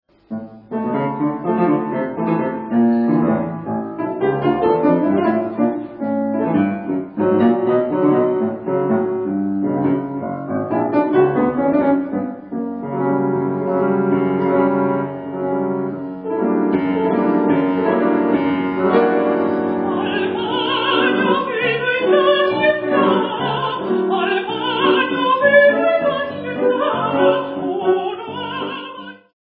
Sopran Theodor-Zink-Museum, Kaiserslautern Konzertmittschnitt